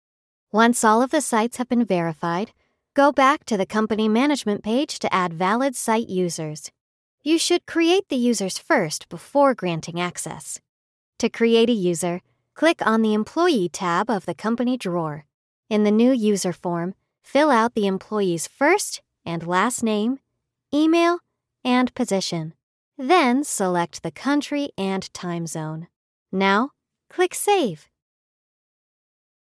Explainer Videos
I have a wonderfully weird, bouncy voice that has inspired many a collaborator to think about their works in entirely new lights.
-StudioBricks double-walled insulated vocal booth